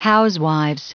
Prononciation du mot housewives en anglais (fichier audio)
Prononciation du mot : housewives